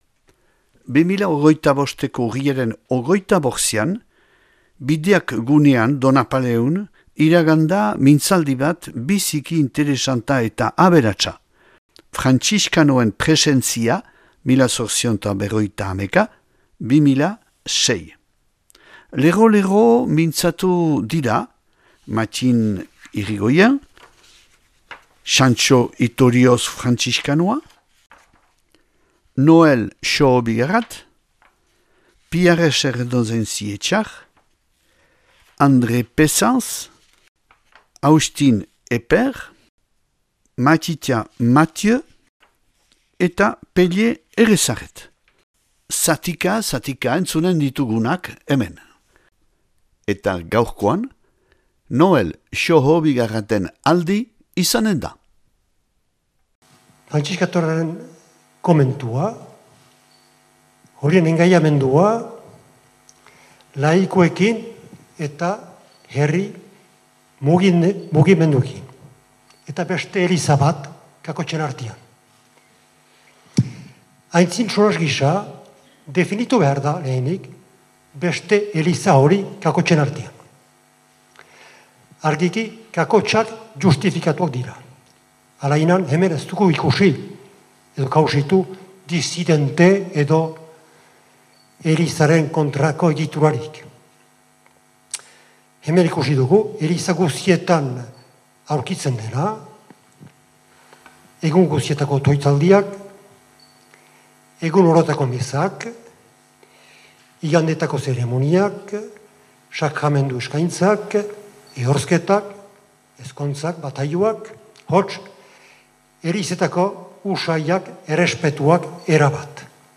2025ko Urriaren 25ean, Bideak gunean Donapaleun, iragan da mintzaldi bat biziki interesanta eta aberatsa : Frantziskanoen presentzia 1851-2006.